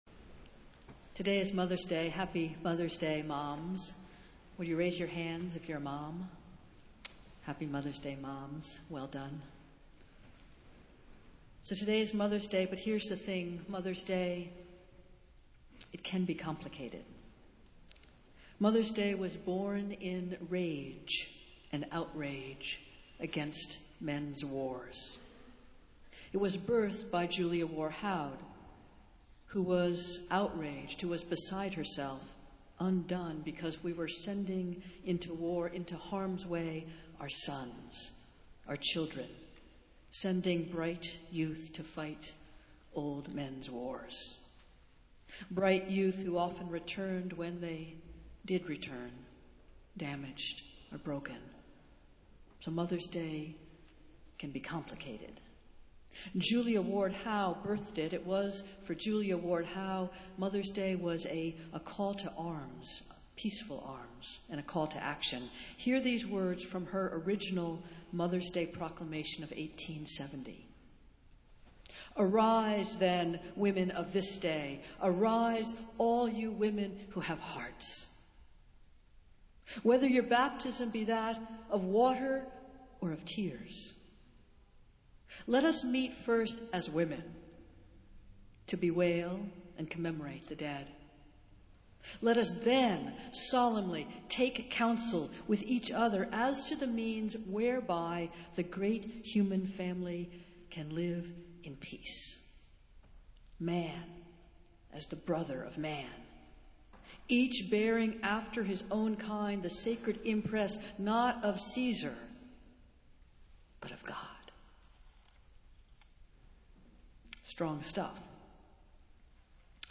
Festival Worship - Phillis Wheatley Sunday